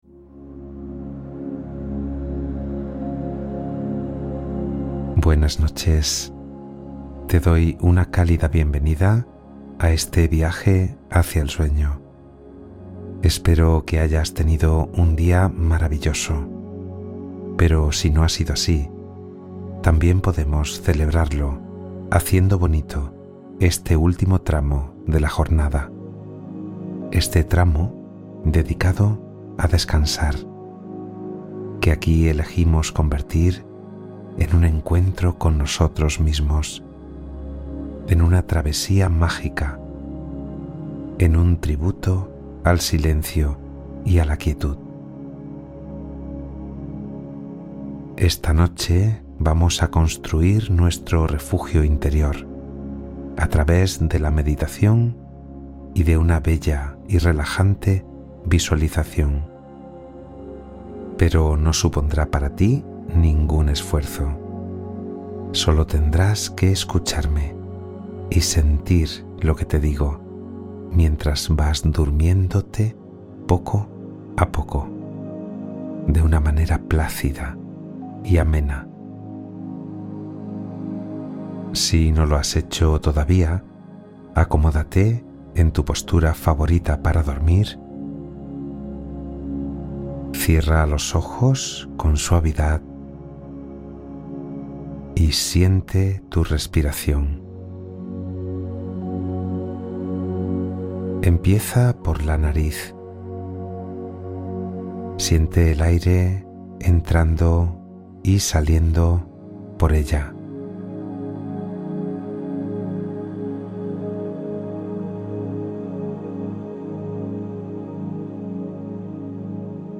Refugio interior: meditación guiada para conciliar el sueño rápidamente